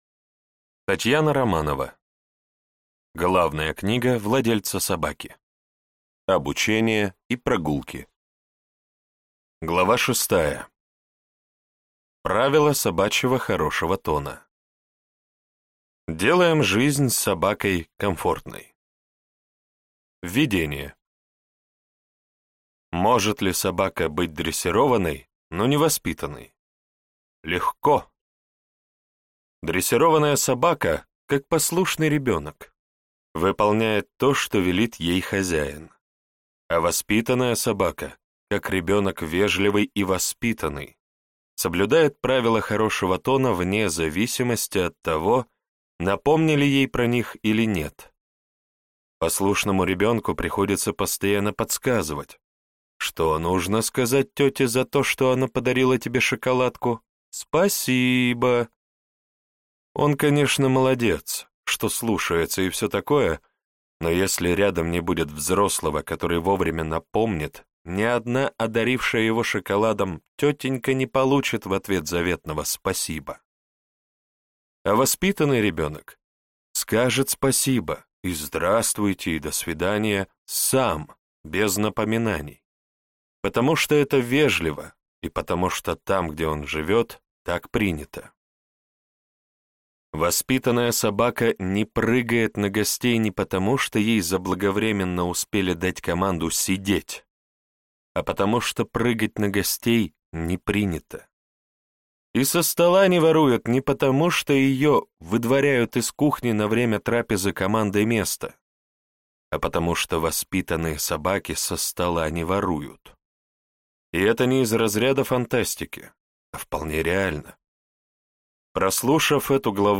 Аудиокнига Главная книга владельца собаки. Обучение и прогулки | Библиотека аудиокниг